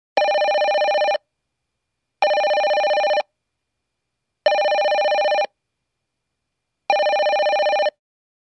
Звуки звонящего телефона
Телефонный звонок - Альтернатива 2